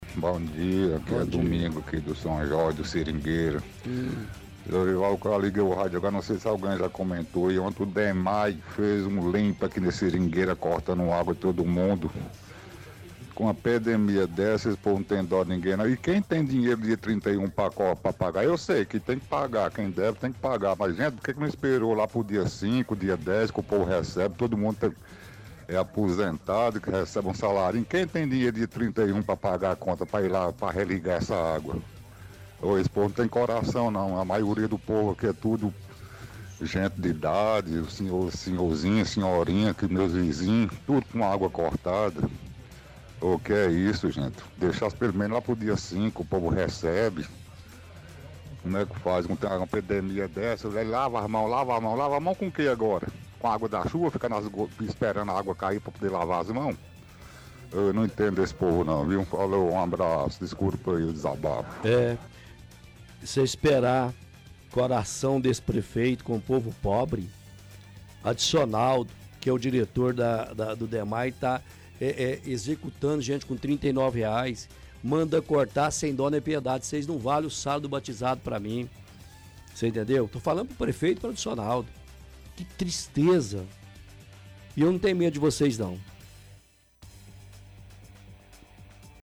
– Ouvinte reclama que o Dmae fez vários cortes de água no bairro Seringueira.